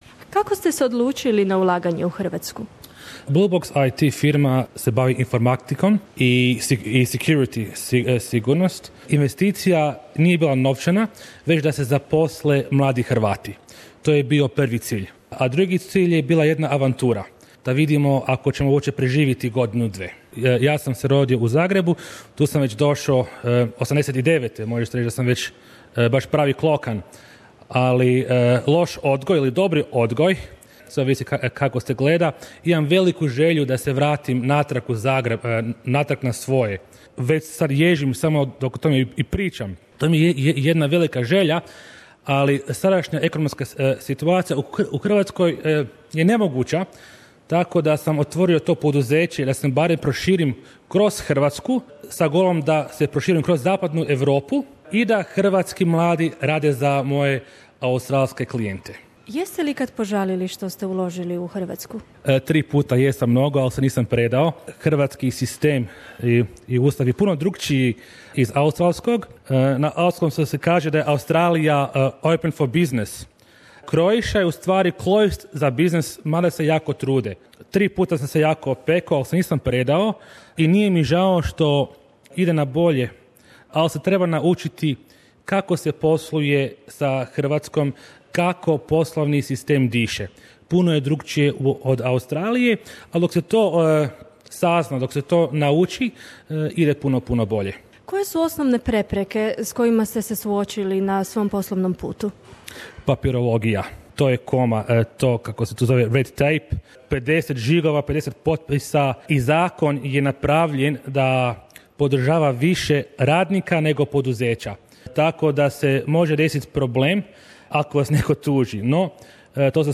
Razgovori s poduzetnicima - povratnicima: